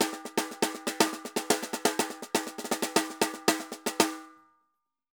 Tambor_Candombe 120_1.wav